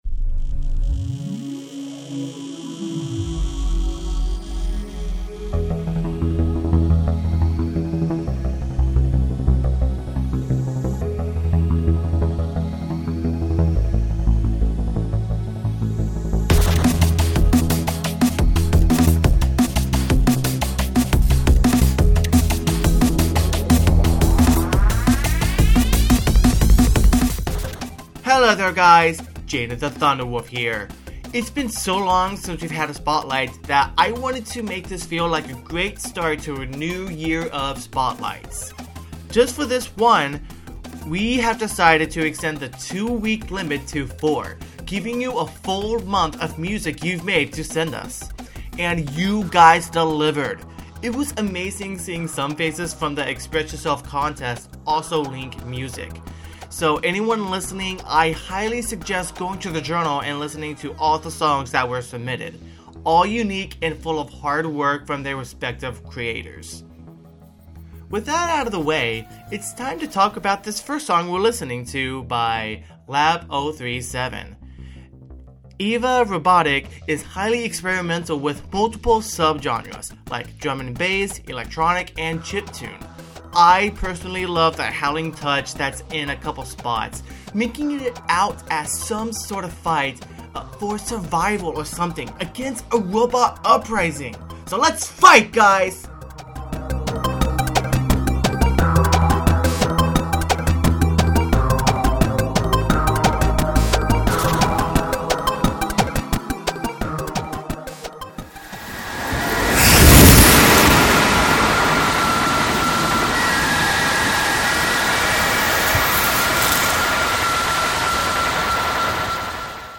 Experimental
Industrial
Lo-Fi
Piano Cover
Rock Musical
(Electronic)